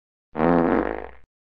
Fart Sound Effect Free Download
Fart